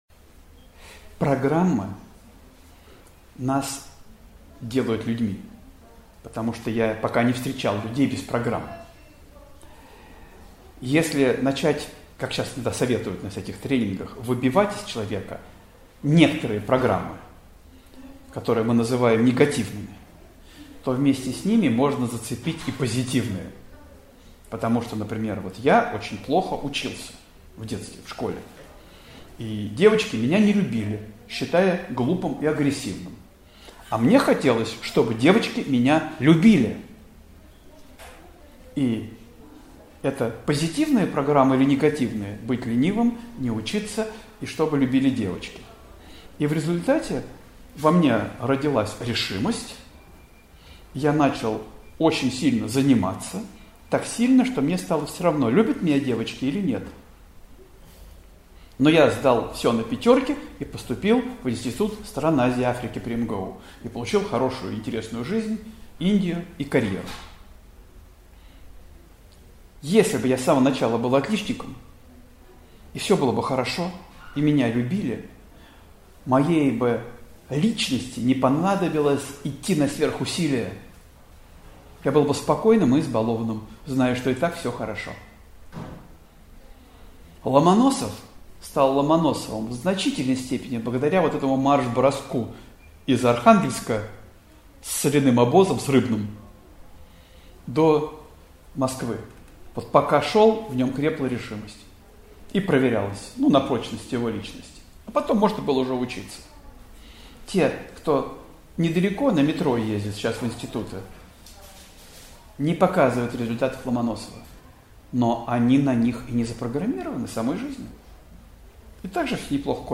Аудиокнига Код спасения - осознанность | Библиотека аудиокниг